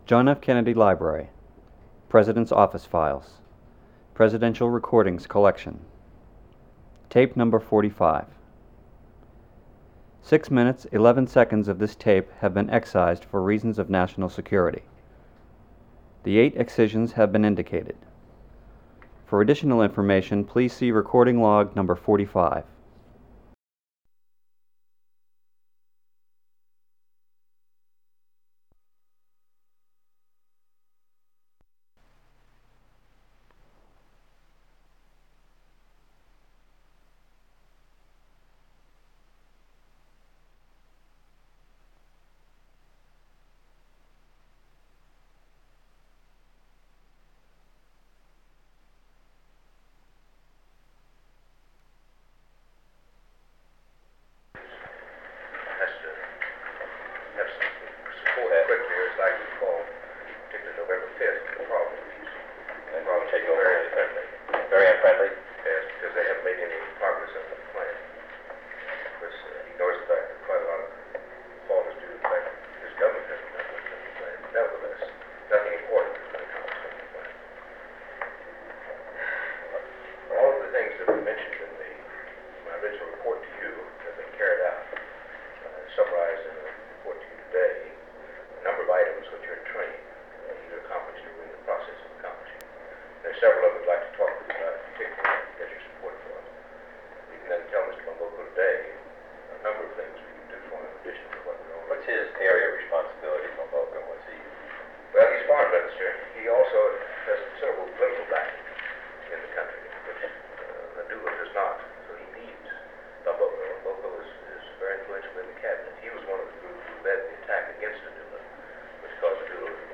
Meeting on the Congo
Secret White House Tapes | John F. Kennedy Presidency Meeting on the Congo Rewind 10 seconds Play/Pause Fast-forward 10 seconds 0:00 Download audio Previous Meetings: Tape 121/A57.